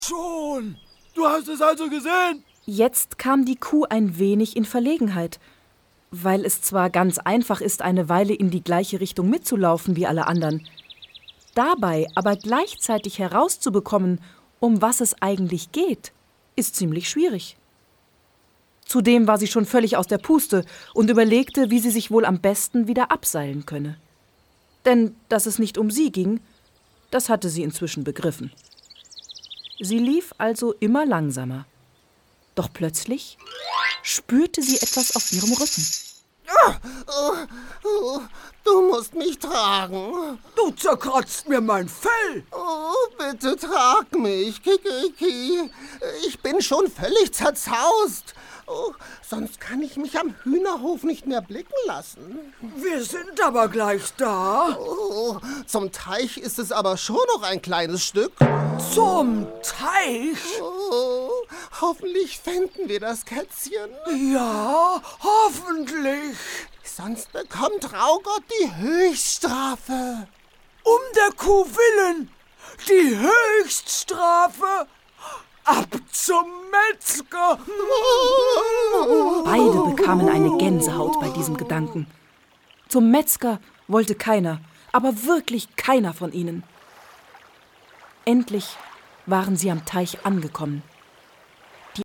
Traugott, das Warzenschwein Hörspiel